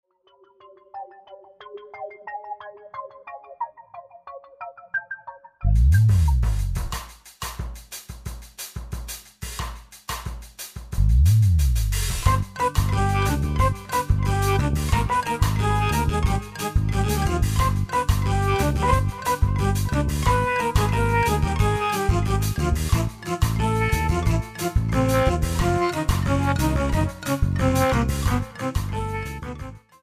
Keyboards, Computer
Piano Ballad, Elektro, Kinderlied,
Techno, Rock ...